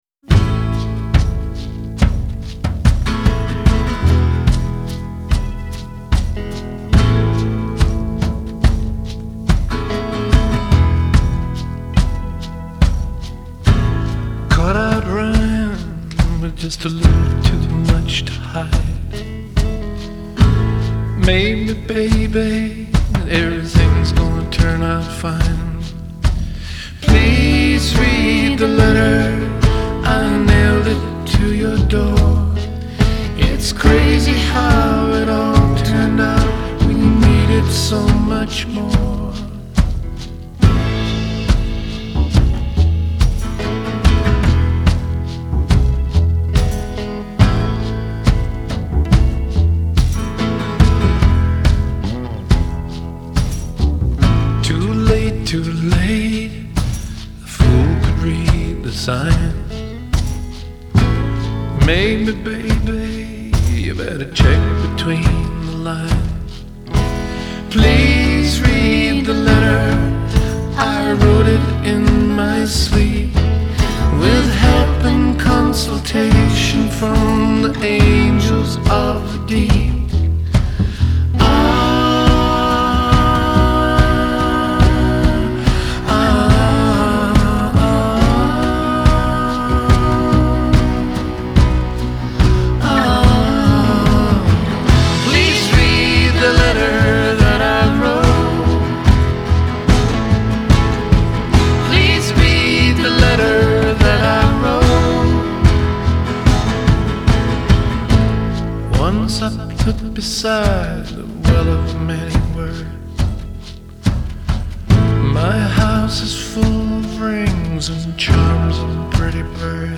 Genre : Folk